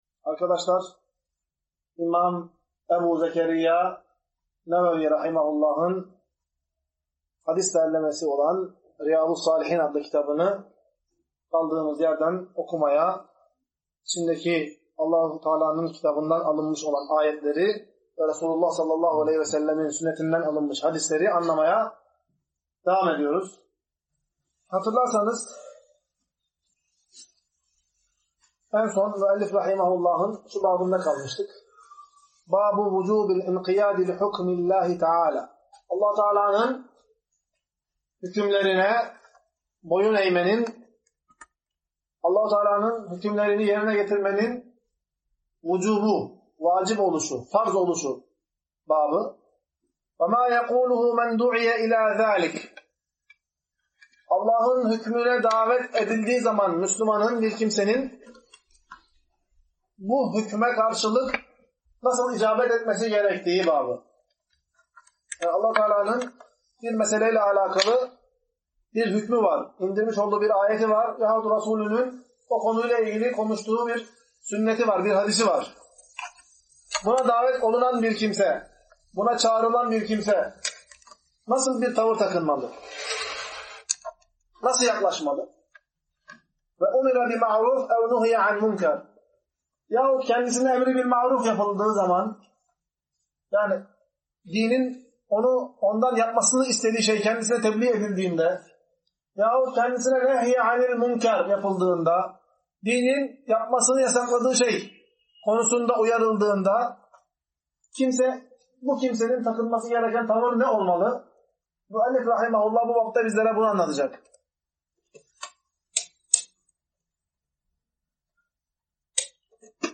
Ders - 17.